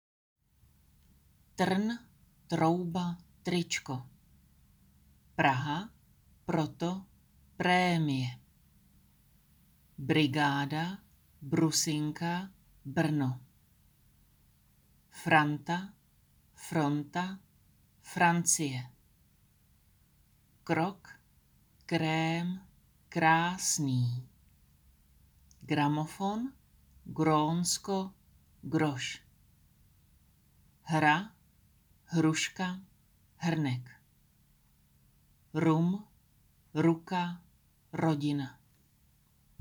Tady si můžete poslechnout audio na výslovnost R-slova.